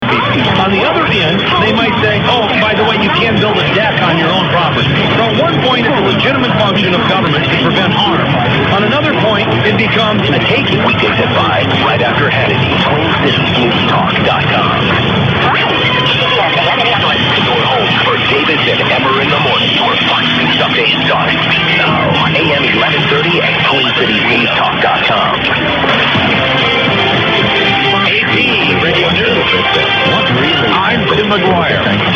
A FEW AUDIO CLIPS OF RECENT RECEPTION:
111120_0800_1230_wsoo_st_marie.mp3